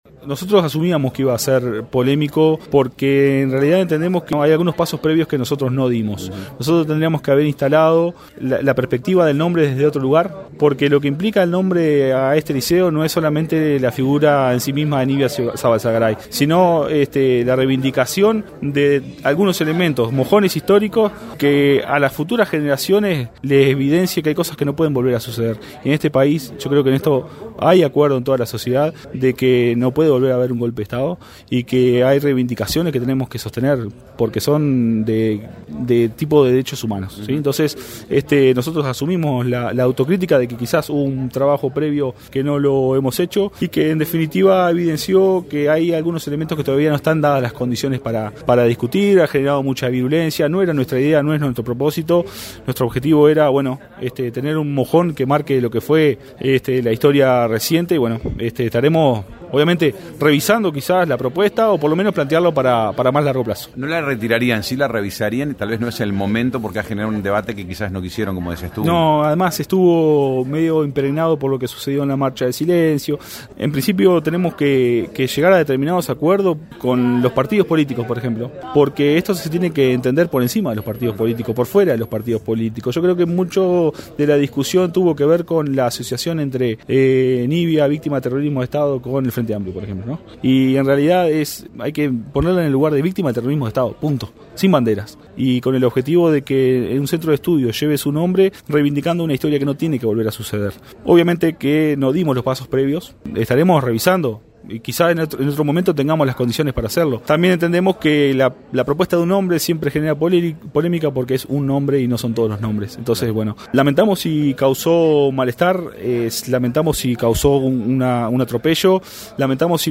Este sábado, en diálogo con Radio del Oeste